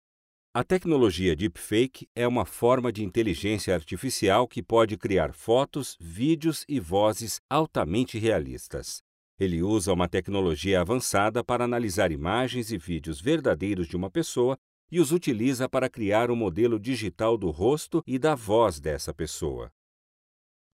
Kommerziell, Natürlich, Zuverlässig, Freundlich, Corporate
Audioguide
His voice is gentle, friendly and conveys reliability.